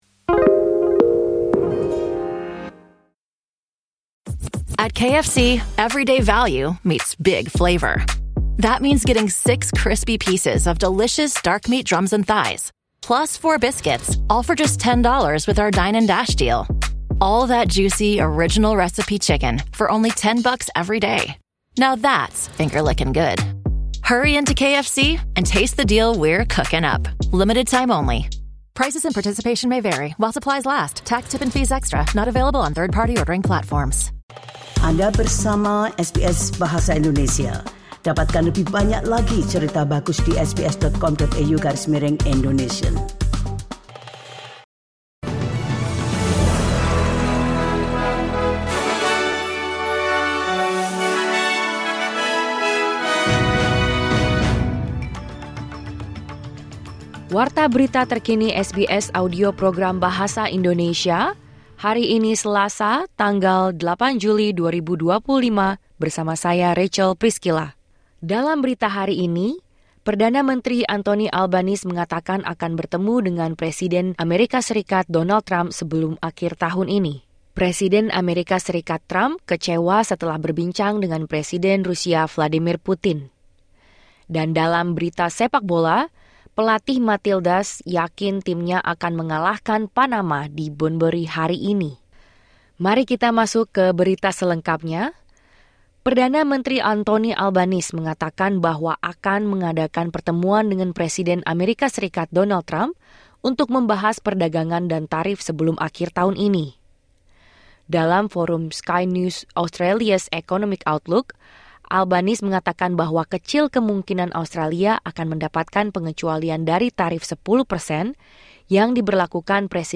Berita Terkini SBS Audio Program Bahasa Indonesia